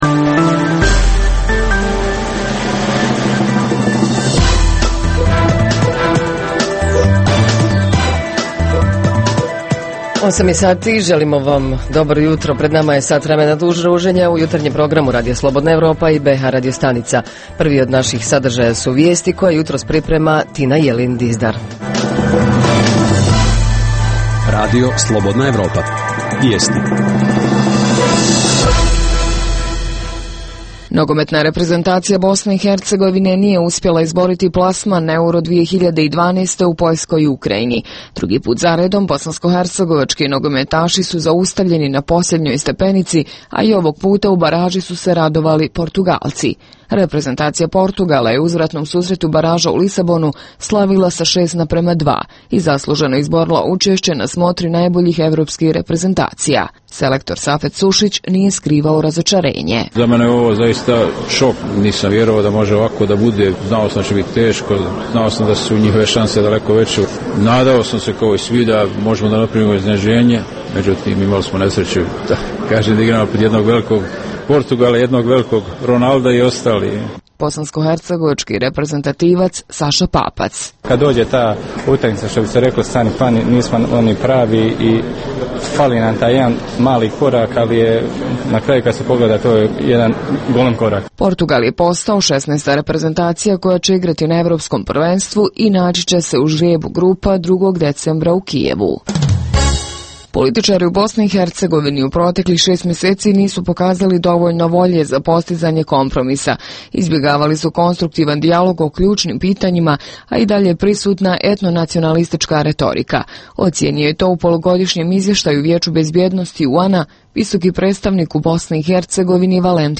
Tema jutra: 16. novembar - Međunarodni dan tolernacije - to je nešto što nam tako nedostaje na svim nivoima, od individualnog, porodičnog, društvenog, pa sve do svih struktura vlasti i političke komunikacije. Reporteri iz cijele BiH javljaju o najaktuelnijim događajima u njihovim sredinama.